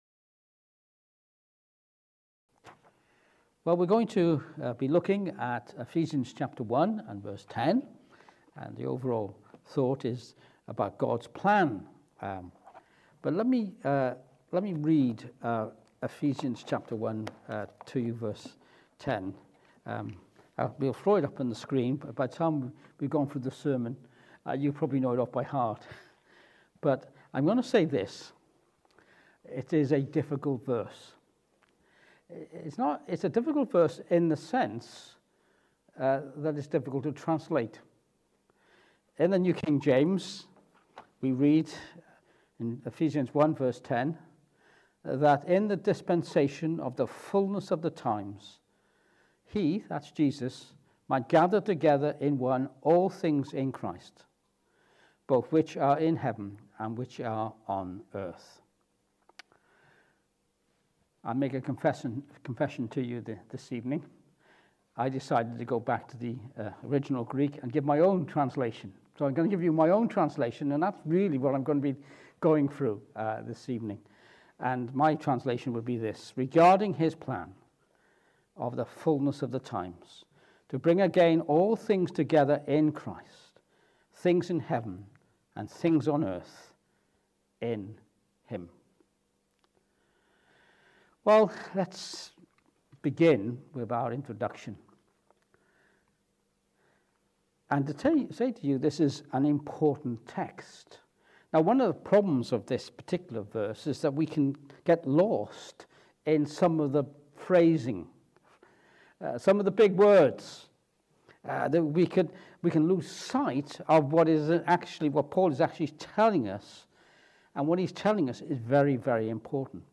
Ephesians 1:10 Service Type: Evening Service This evening we turn again to Ephesians chapter one.